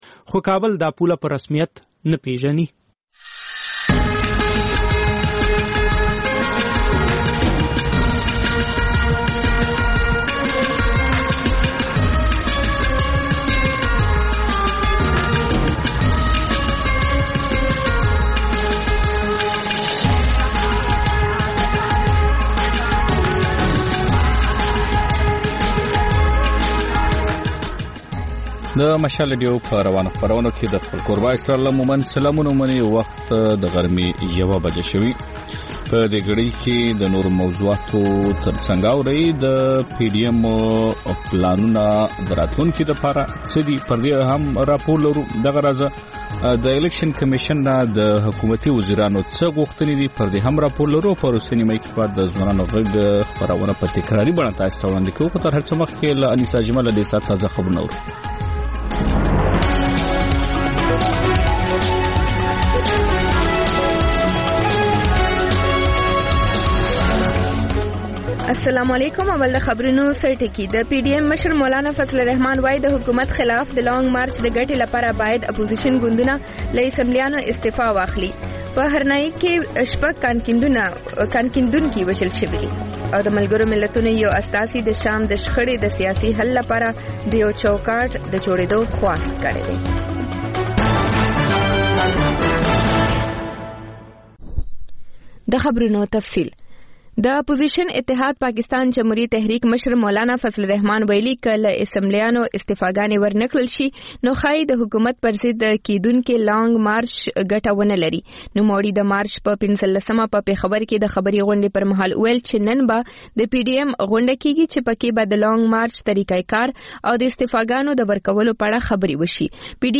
د مشال راډیو لومړۍ ماسپښينۍ خپرونه. په دې خپرونه کې تر خبرونو وروسته بېلا بېل رپورټونه، شننې، مرکې خپرېږي. ورسره یوه اوونیزه خپرونه هم خپرېږي.